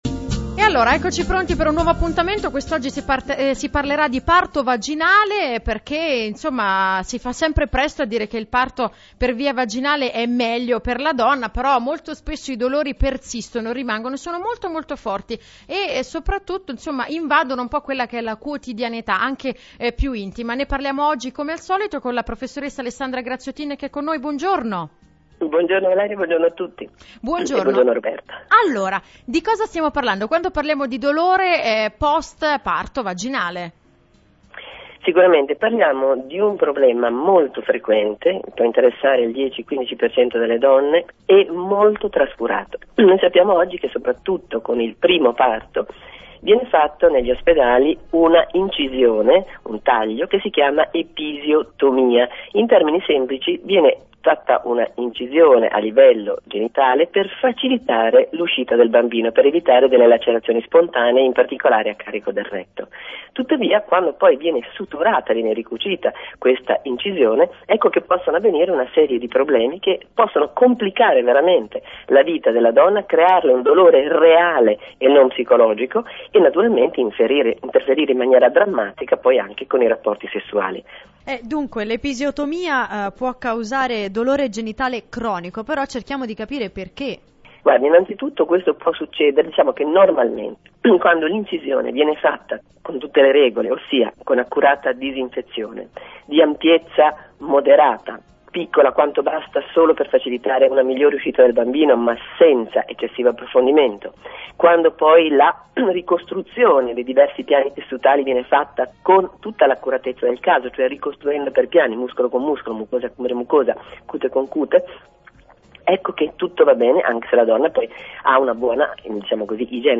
Sintesi dell'intervista e punti chiave